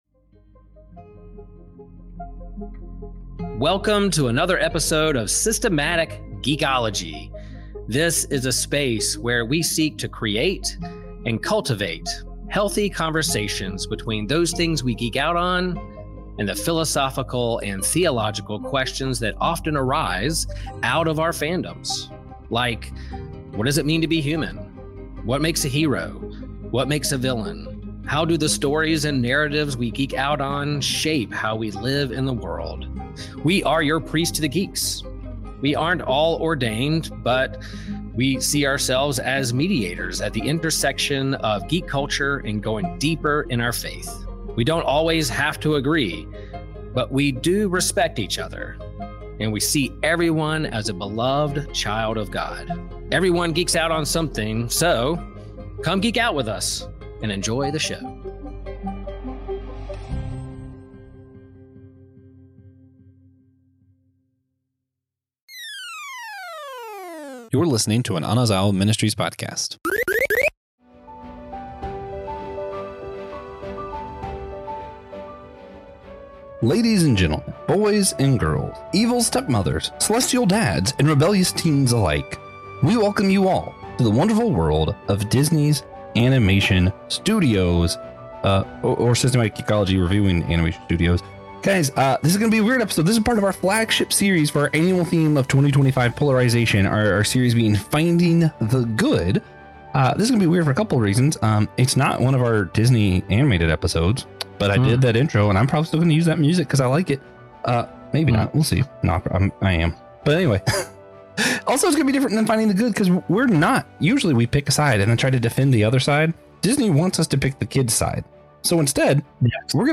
Buckle up for a wild ride filled with clever banter, unique insights, and a touch of humor that'll keep you entertained while pondering the deeper meanings behind these beloved legends.